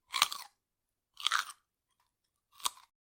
Хруст моркови при кусании, жевание, нарезка и другие звуки в mp3 формате
3. Звук укуса морковки (три раза)